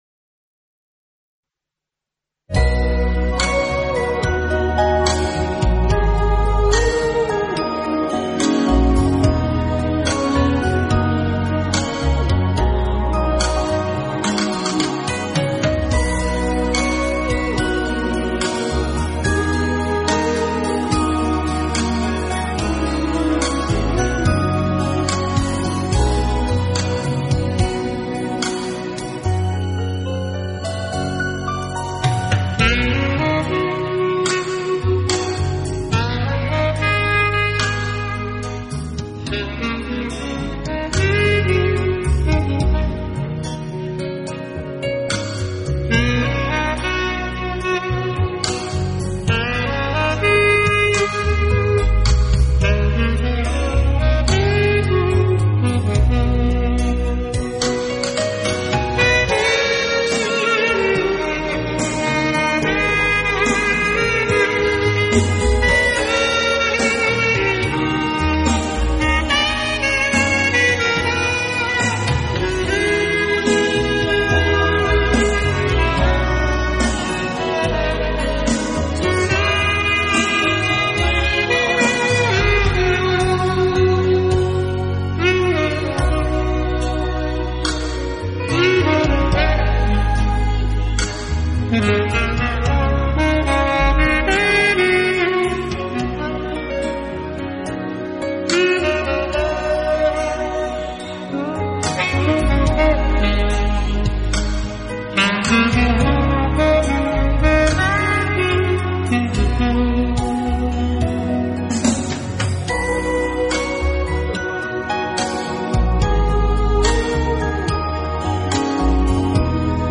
萨克斯，浪漫风情的完美代言人。